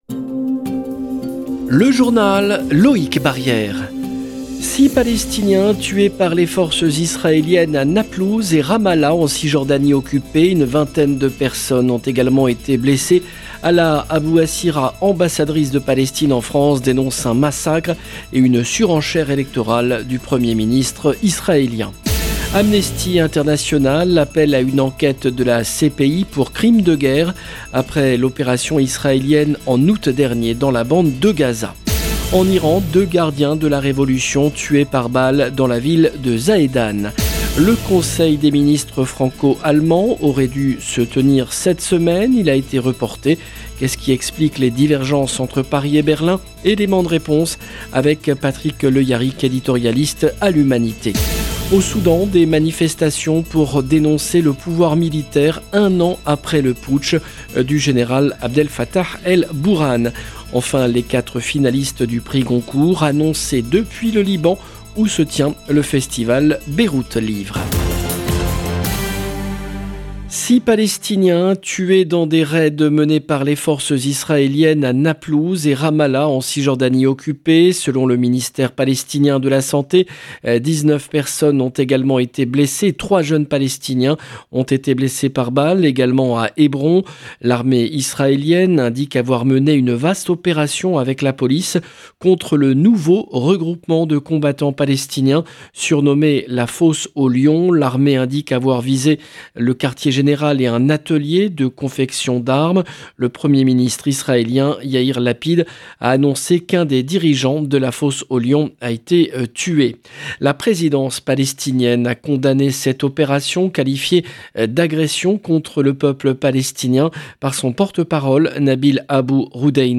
JOURNAL EN LANGUE FRANÇAISE